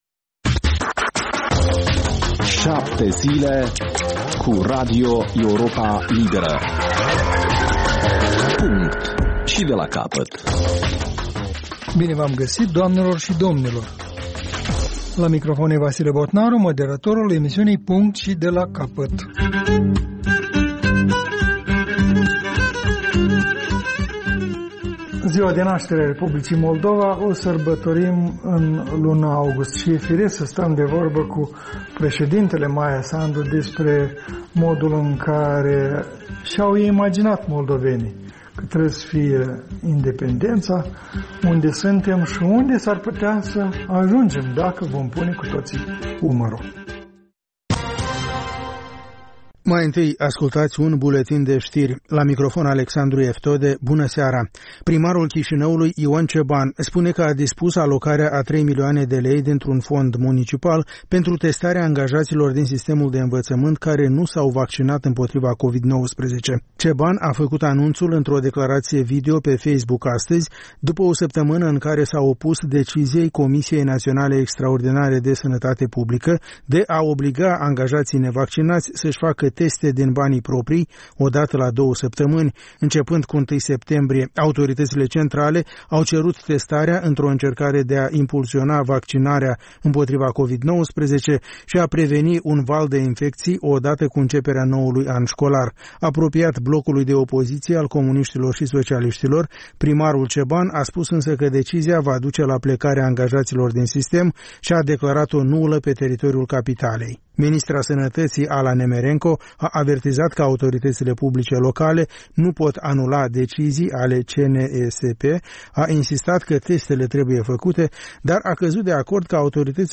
O emisiune în reluare cu un buletin de ştiri actualizat, emisiunea se poate asculta şi pe unde scurte